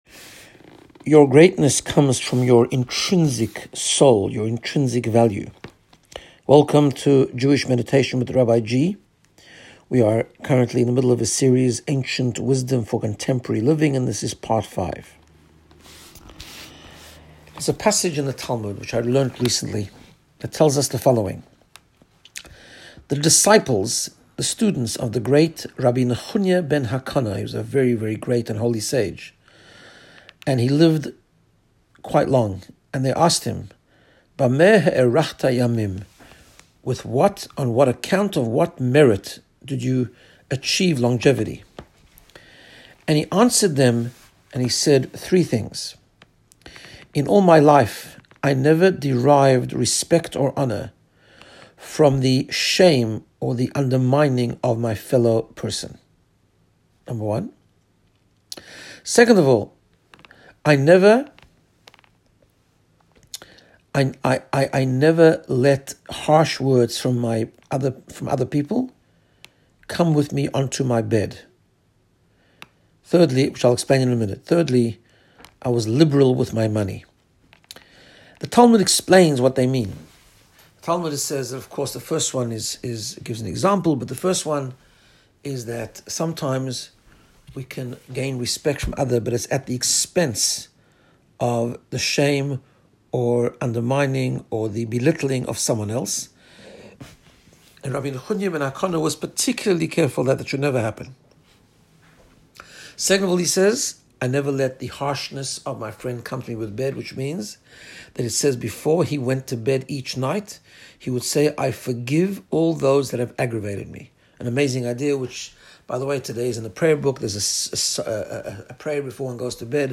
Guided Meditation to get you in the space of Personal Growth and Wellbeing.